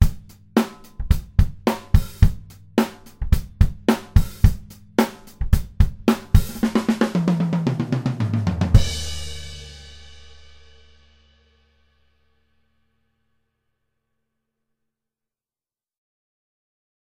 オーディオ・デモ
近接効果のない原音に忠実で透明なサウンド
アコースティック・ギター